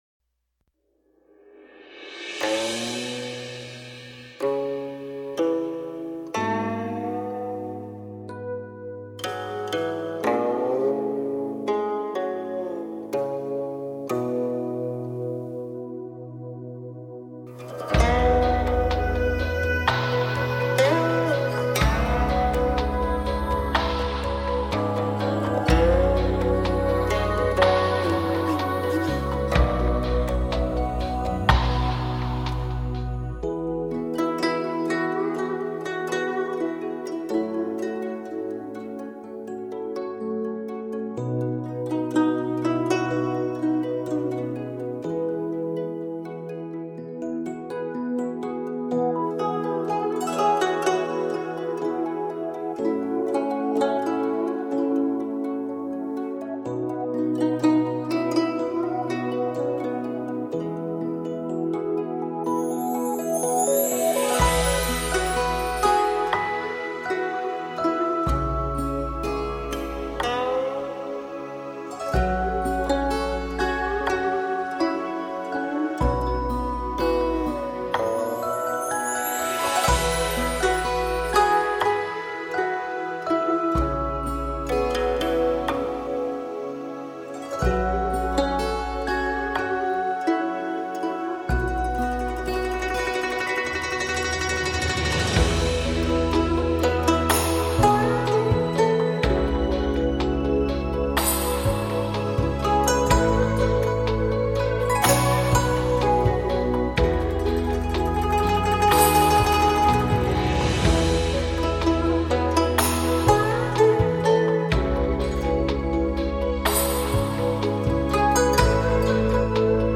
美人古筝发烧天碟，绝怀美丽珍藏。
筝弦的颗粒感饱满自然，完全释放出女人如花似玉的芬芳，清雅的让人怜爱让人疼惜。
搭配古琴与电吉他更是独具匠心，单听此曲已值回碟价，录音方面质感强烈，是测试器材的杰出之作。